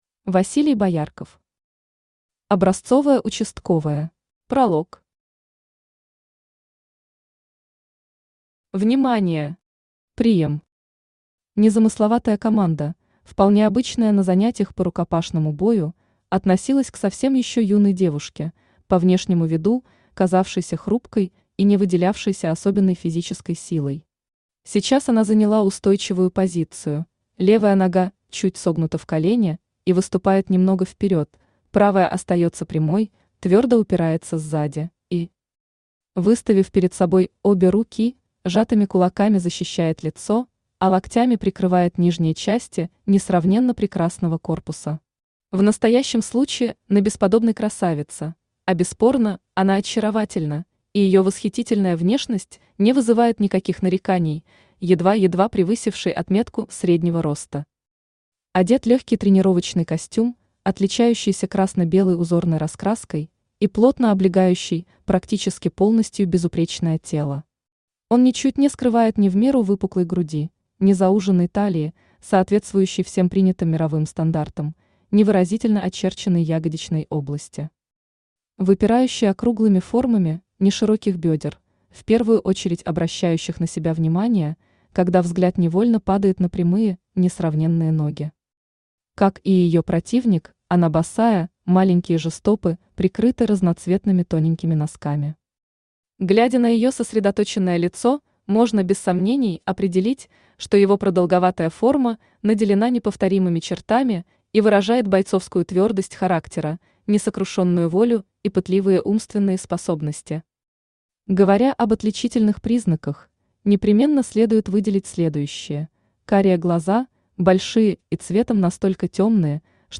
Аудиокнига Образцовая участковая | Библиотека аудиокниг
Aудиокнига Образцовая участковая Автор Василий Боярков Читает аудиокнигу Авточтец ЛитРес.